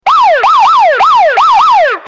Police Sirens 03
Police_sirens_03.mp3